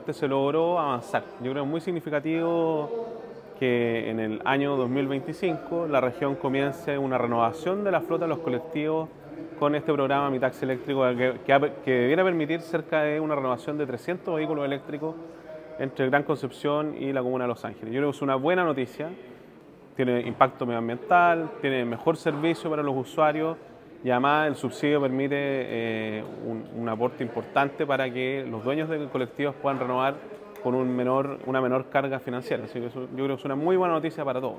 El gobernador, Sergio Giacaman, se refirió al proceso detrás de esta medida.